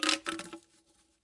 打击乐 " hh6
描述：高帽打击乐在PC上生成和处理。
标签： 产生 HH 高帽子 四氯乙烯 打击乐器 处理 合成器
声道立体声